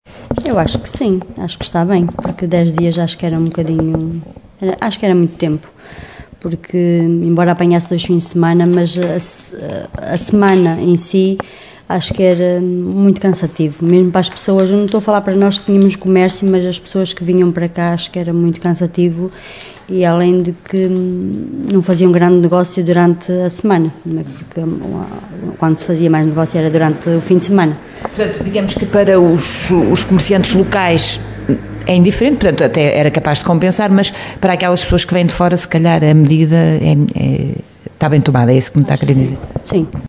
A Rádio Caminha saiu à rua para ouvir comerciantes e instituições do concelho e percebeu que as opiniões dividem-se, mas a maioria dos auscultados não concorda com a decisão do presidente da Câmara.